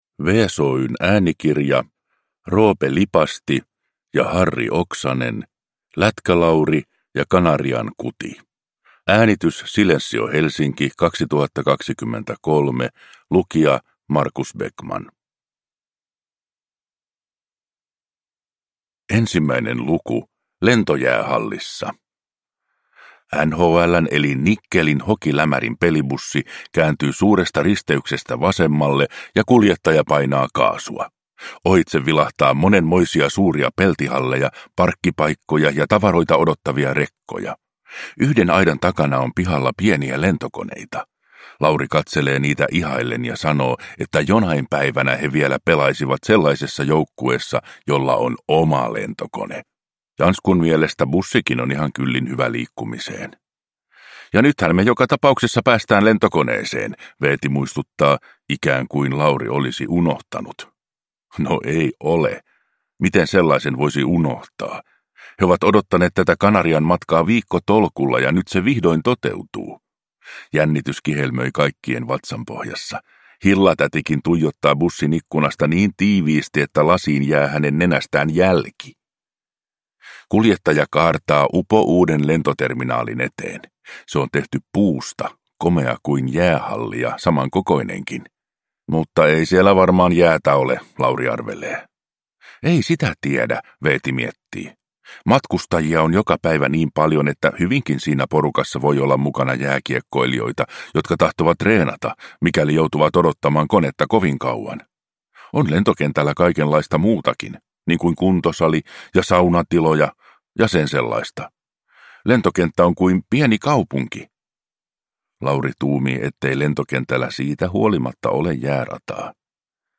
Lätkä-Lauri ja Kanarian kuti – Ljudbok – Laddas ner